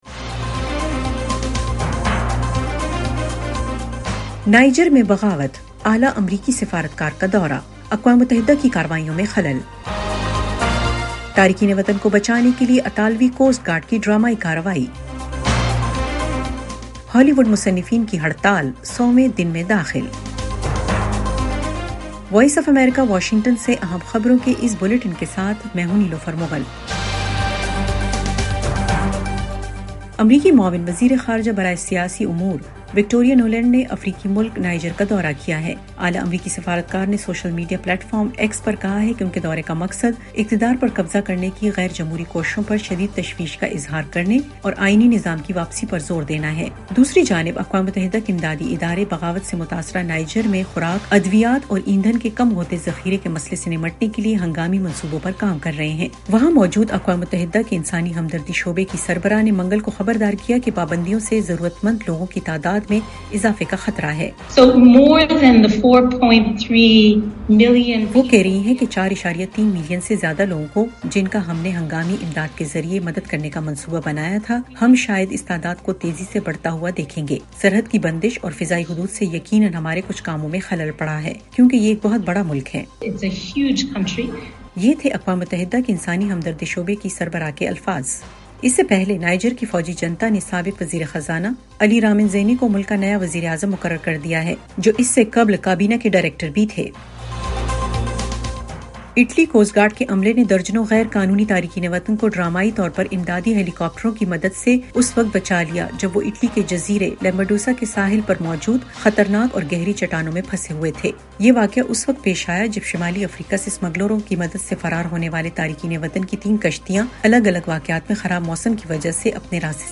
ایف ایم ریڈیو نیوز بلیٹن : رات 10 بجے